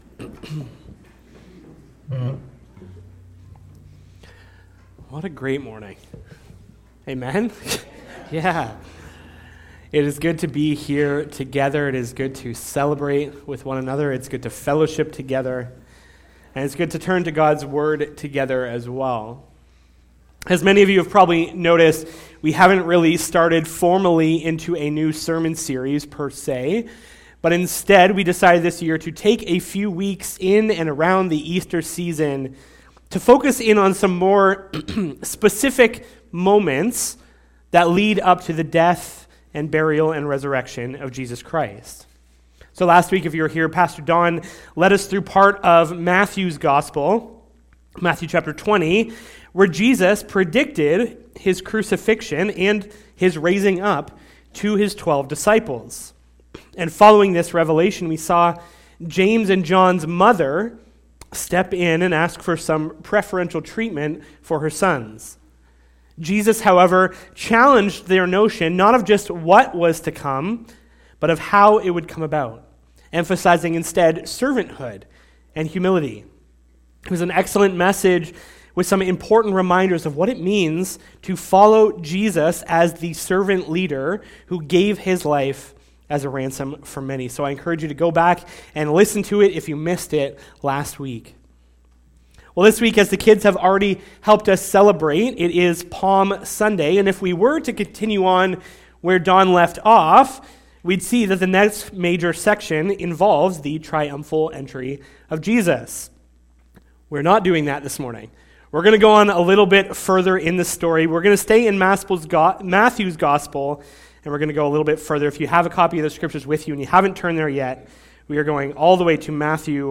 Sermon Audio and Video True Strength